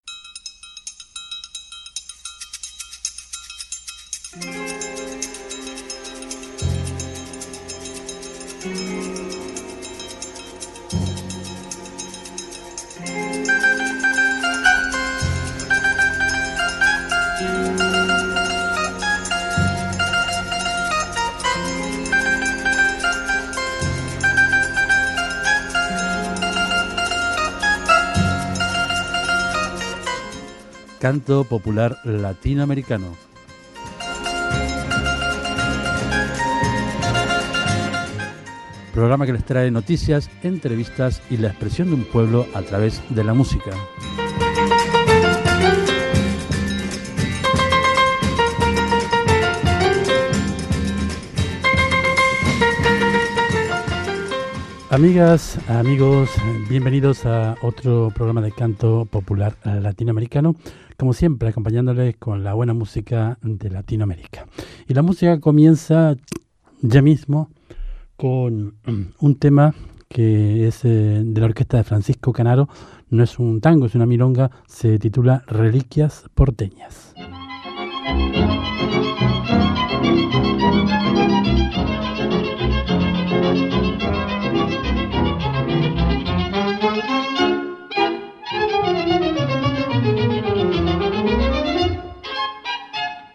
Musical
Fragment extret de l'arxiu sonor de COM Ràdio